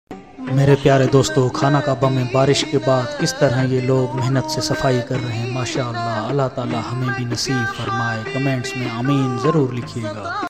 Khana Kaba Makkah Mein Barish sound effects free download